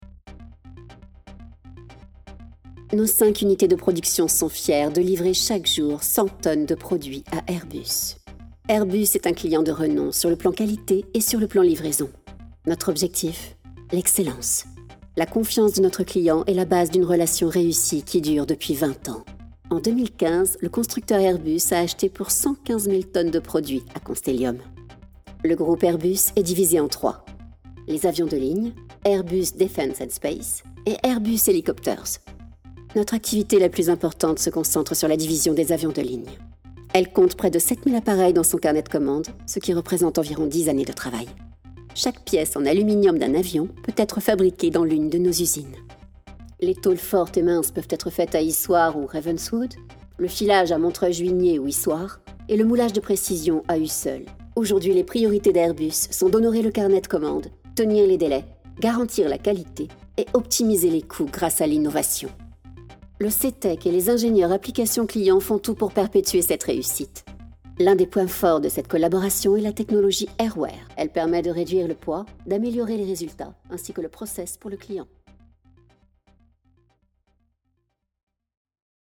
EXTRAITS VOIX NARRATION ET DOUBLAGES